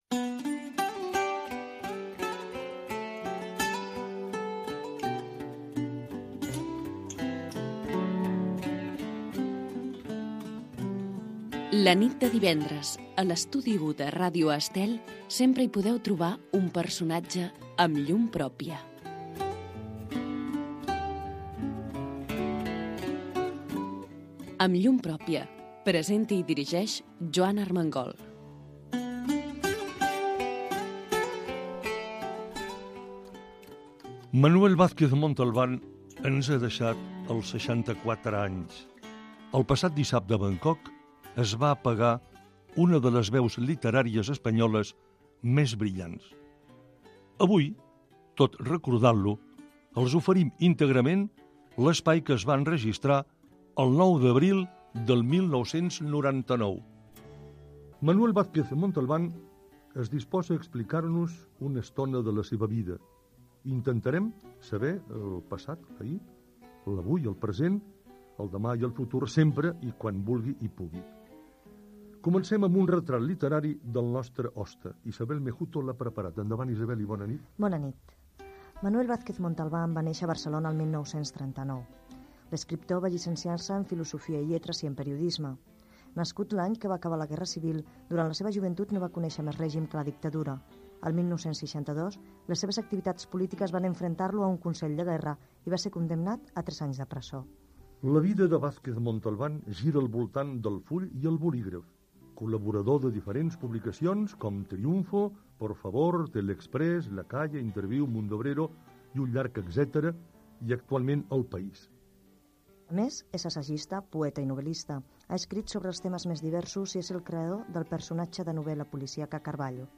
Programa d'entrevistes en profunditat a personalitats del món de la política, la societat, la cultura i els esports, que es va emetre a la sintonia de Ràdio Estel des de 1996 fins el 2012.